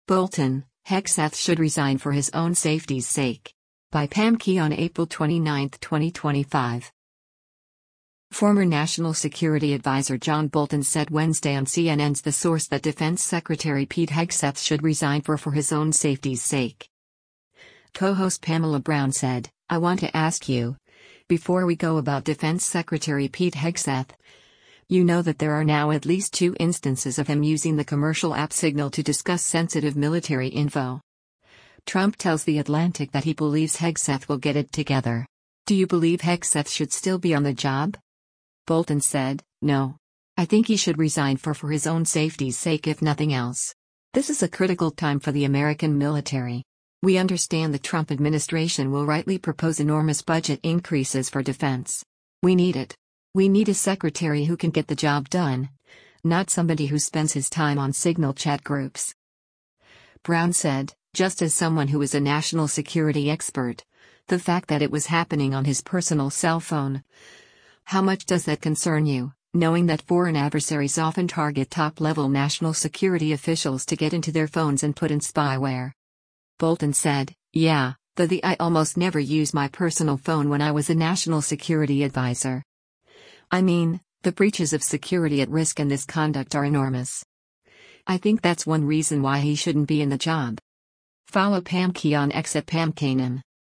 Former National Security Advisor John Bolton said Wednesday on CNN’s “The Source” that Defense Secretary Pete Hegseth should “resign for for his own safety’s sake.”